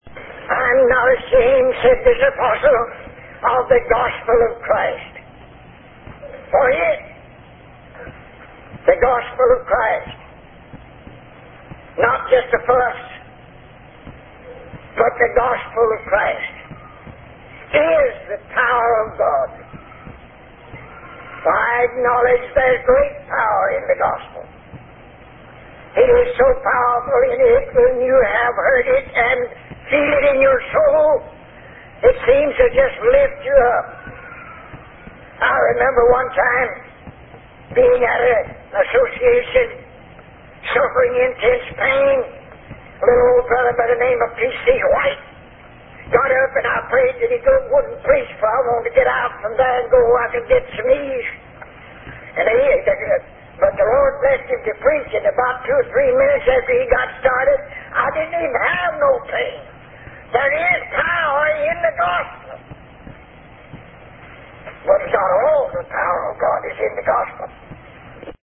Sermon Excerpt Power in the Gospel
at the 1983 Mt. Zion Association of Alabama